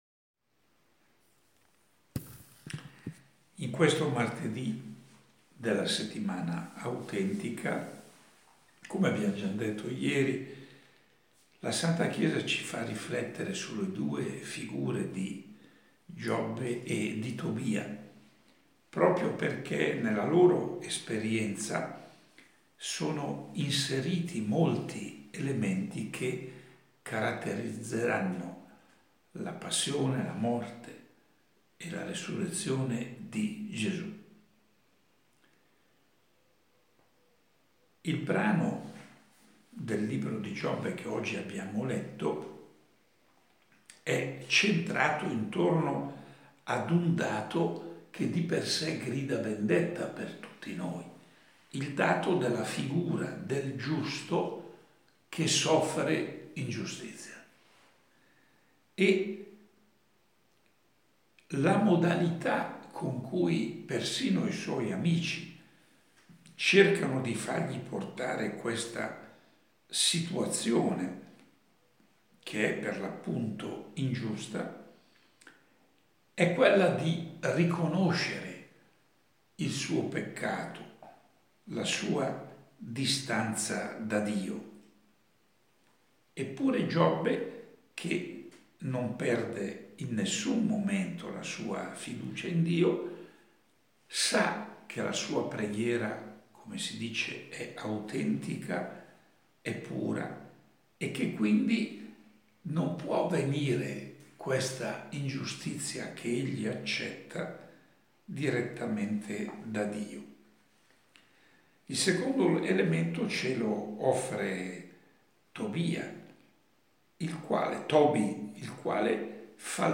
Proponiamo la registrazione audio dell’omelia pronunciata dal cardinale Scola il 7 aprile 2020, martedì della Settimana Autentica, presso la cappellina della sua abitazione di Imberido (LC).